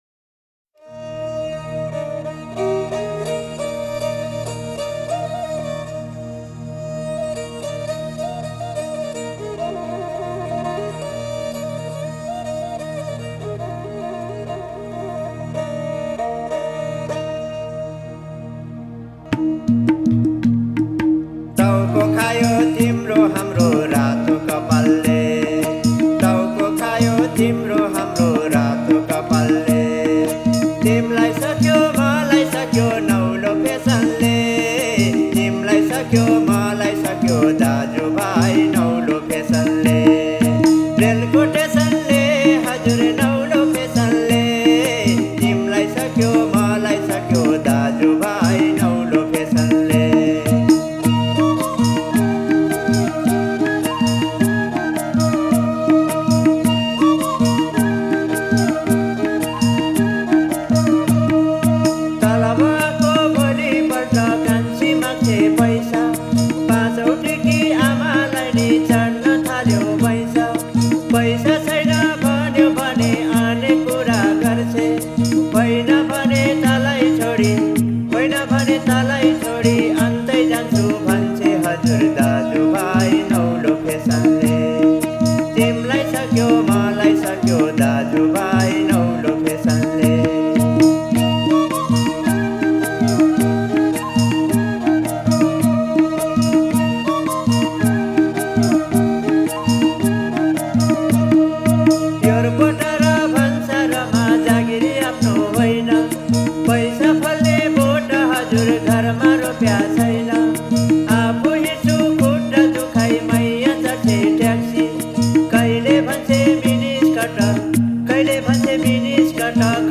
Musique traditionnelle népalaise (mp3 - 5.7mb)